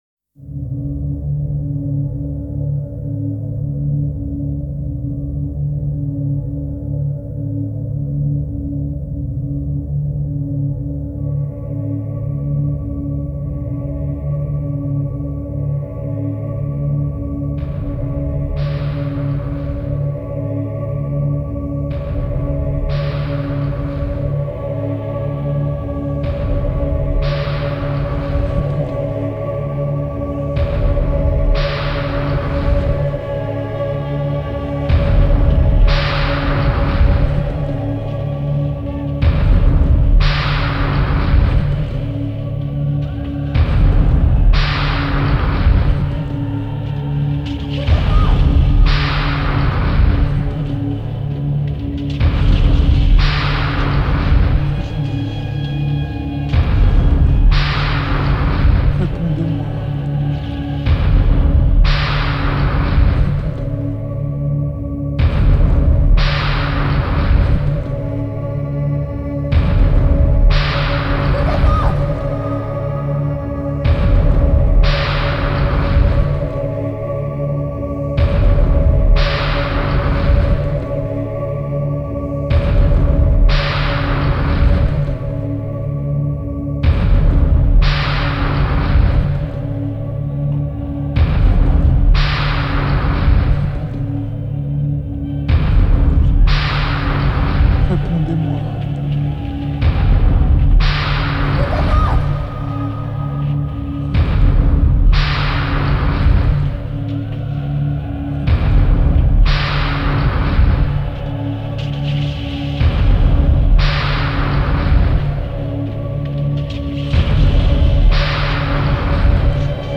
literature, ancient rites and modern electronics